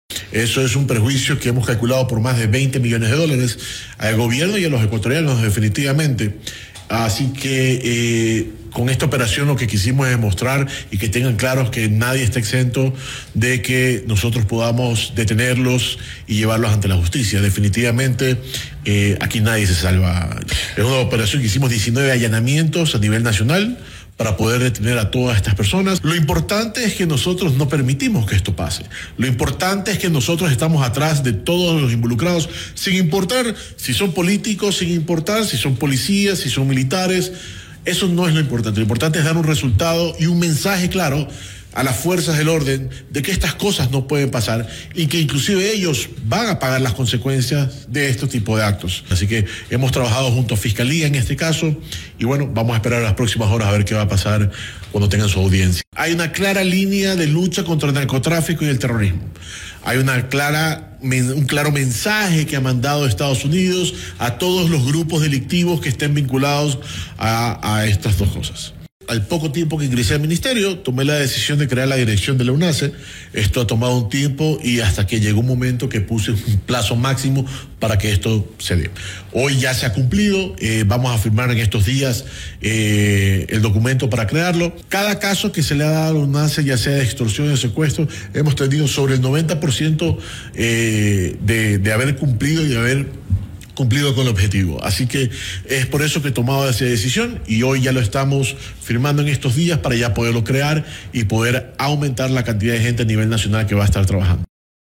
𝗠𝗶𝗻𝗶𝘀𝘁𝗿𝗼-𝗝𝗼𝗵𝗻-𝗥𝗲𝗶𝗺𝗯𝗲𝗿𝗴-𝗡𝗮𝗱𝗶𝗲-𝗲𝘀𝘁a-𝗲𝘅𝗲𝗻𝘁𝗼-𝗱𝗲-𝗹𝗮-𝗷𝘂𝘀𝘁𝗶𝗰𝗶𝗮.-📻-Durante-la-entrevista-con-FM-Mund.mp3